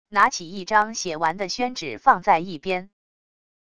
拿起一张写完的宣纸放在一边wav音频